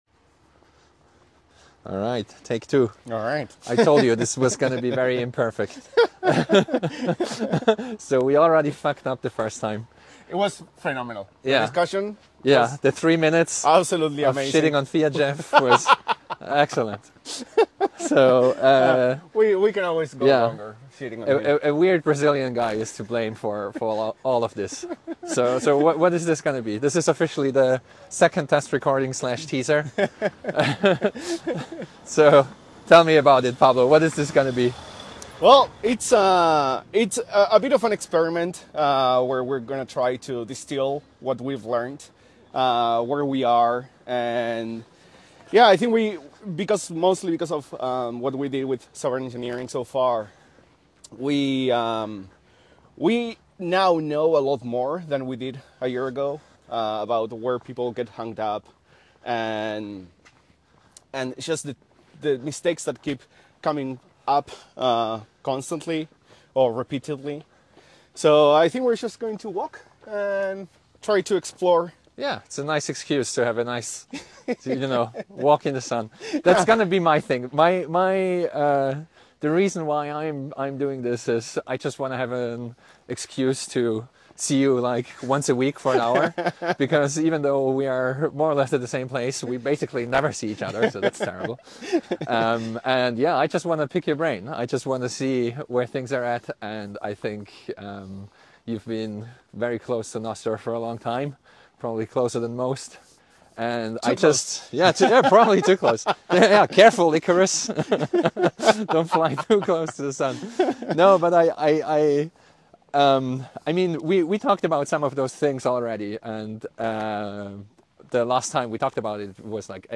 A walking dialogue on what the right trade-off balance might be.